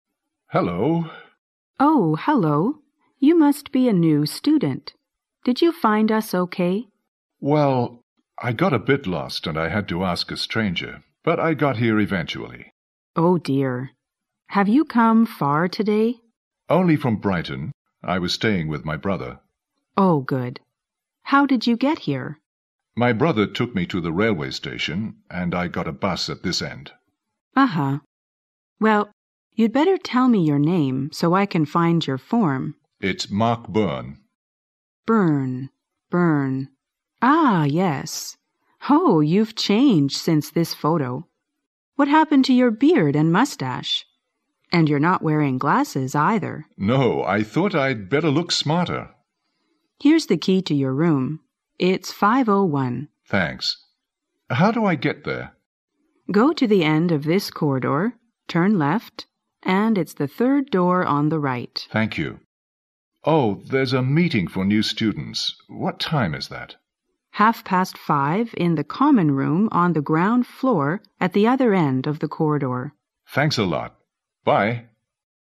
2006年英语专业四级:对话1